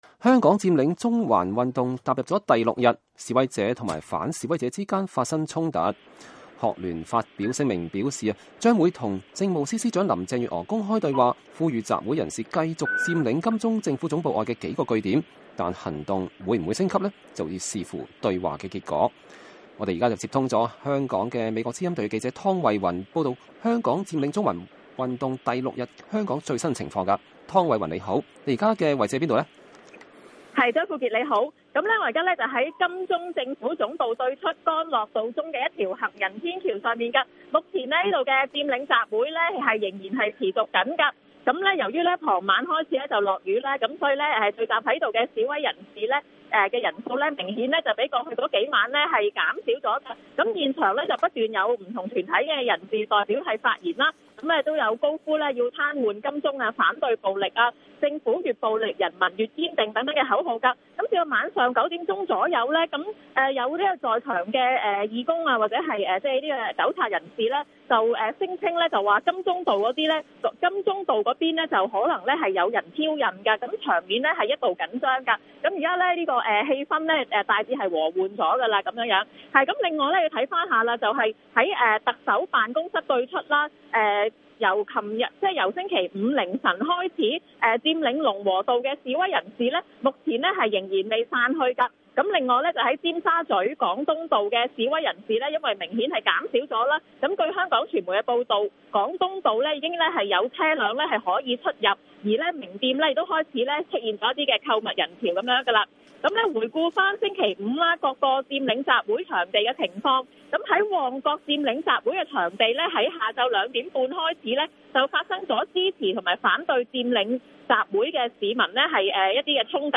現場報導：香港佔中第6日 旺角民眾衝突